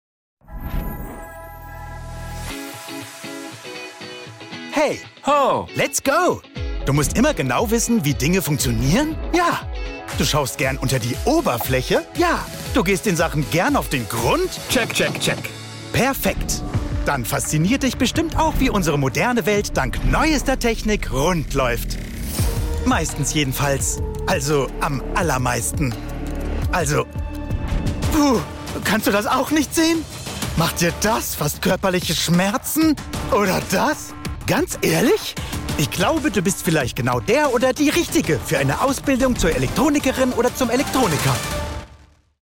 High professional German voice talent: Charming, Emotional, Happy, Hip, Optimistic, Bold, Calm, Charismatic, Cool, Emotional, Motivational
Sprechprobe: Sonstiges (Muttersprache):